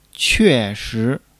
que4--shi2.mp3